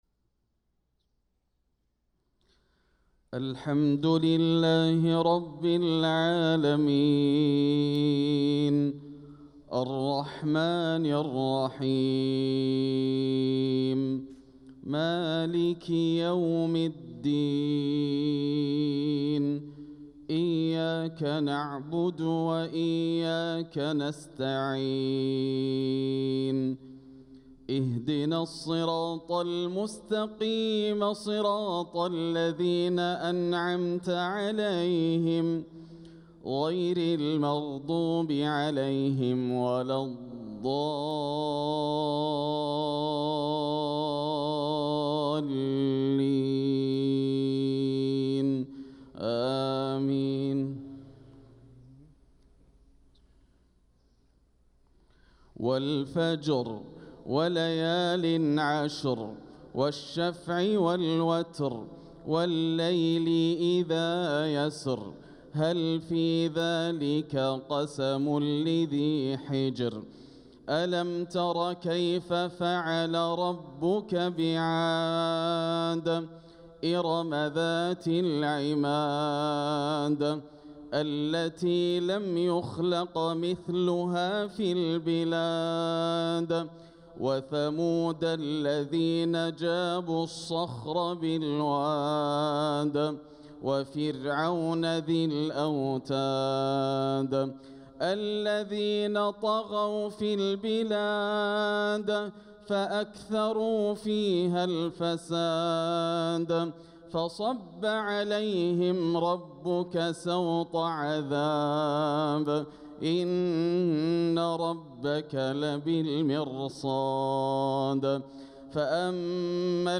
صلاة الفجر للقارئ ياسر الدوسري 16 صفر 1446 هـ
تِلَاوَات الْحَرَمَيْن .